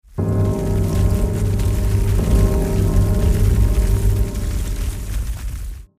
地藏敲钟及灰尘.mp3